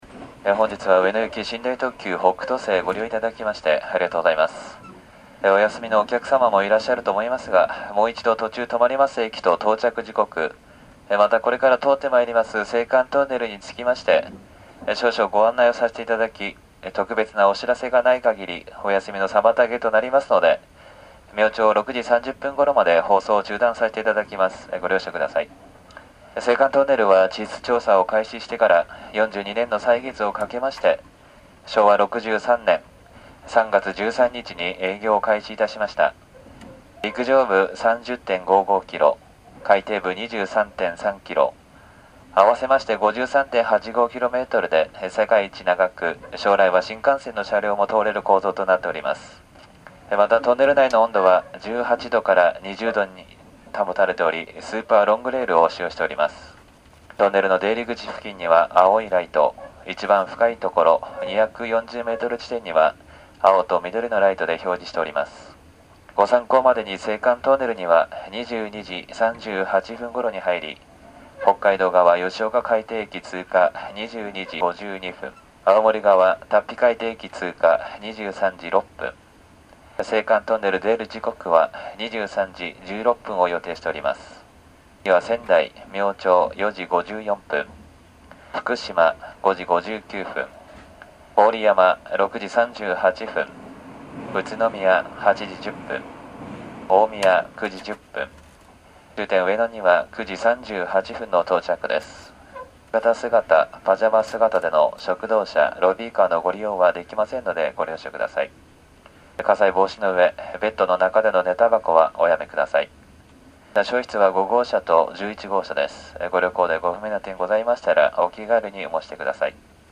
今日最後のお休み放送（再生時間2分06秒）